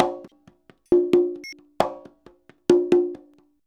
133CONGA01-R.wav